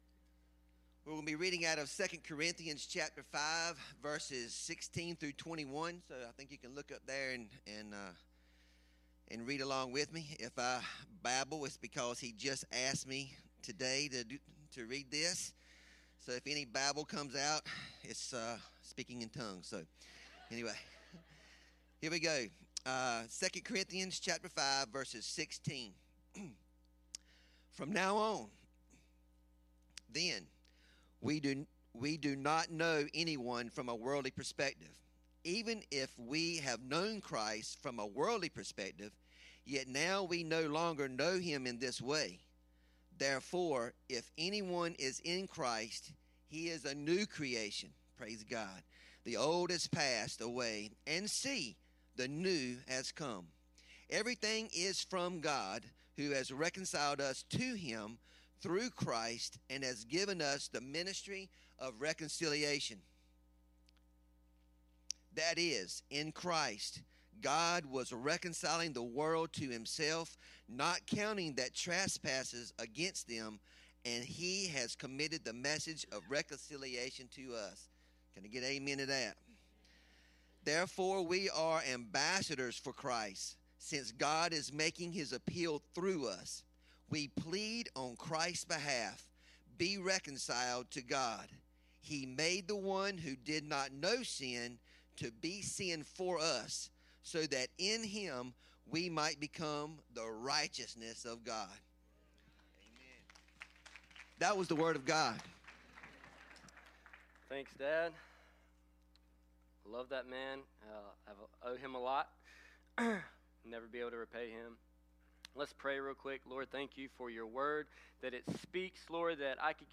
Service Type: Sunday 10am